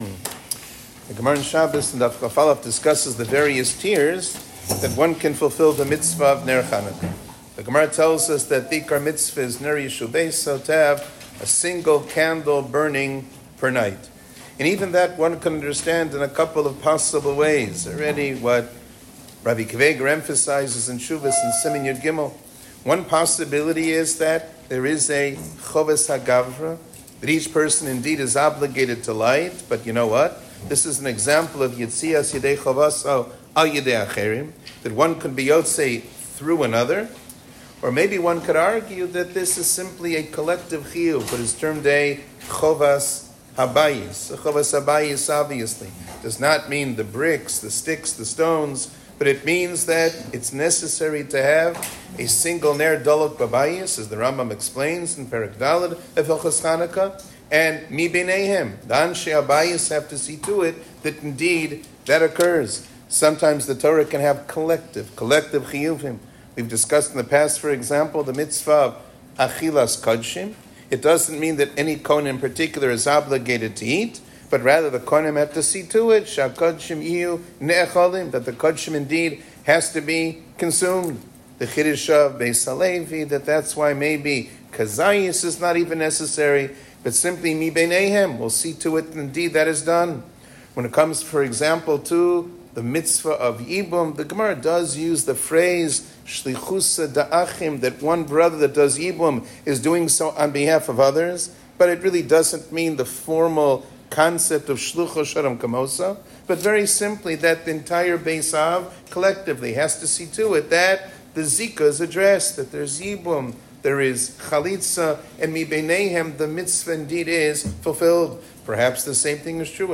שיעור כללי - הידור מצוה בנר חנוכה